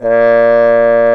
Index of /90_sSampleCDs/Roland L-CDX-03 Disk 2/BRS_Trombone/BRS_TromboneMute